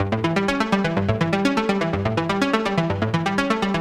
SYNTH GENERAL-3 0001.wav